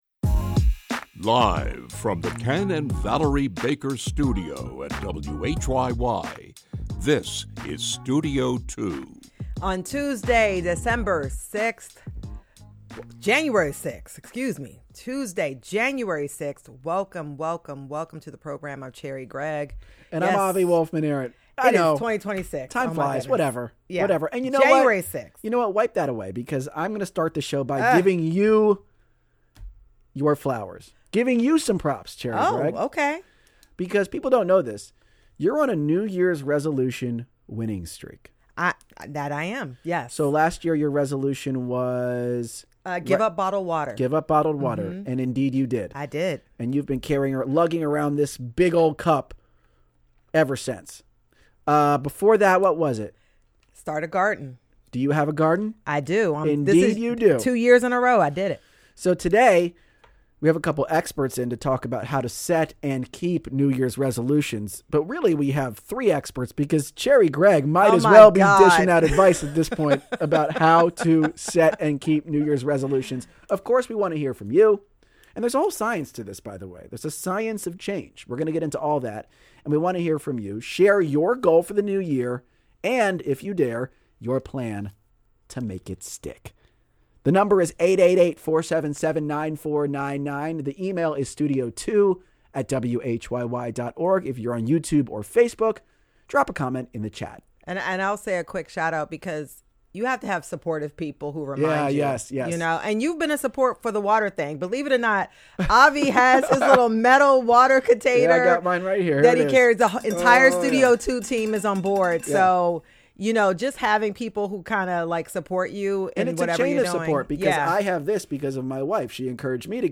In their weekly conversation